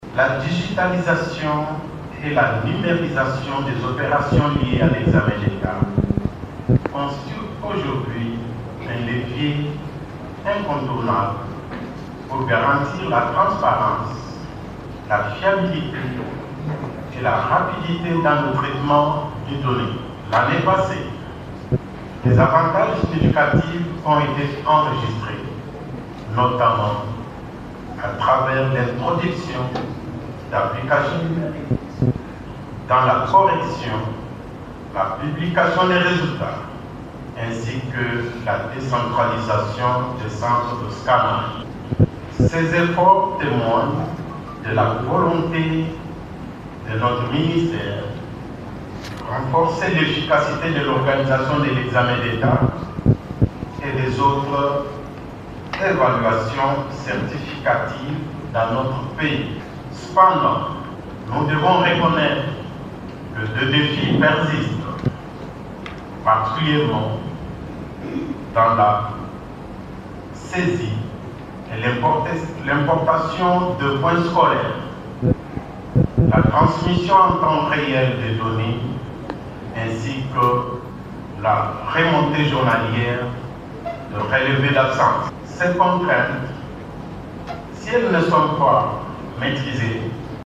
Il organise, du 23 au 26 février à Kinshasa, un atelier sur la modernisation et l’optimisation du processus de gestion des données de cette épreuve nationale.